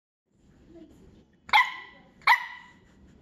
Woof(4).m4a